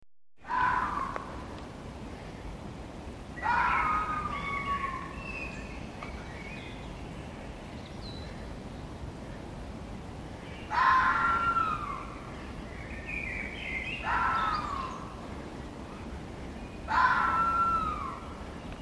fox-scream-in-the-forest-26634.mp3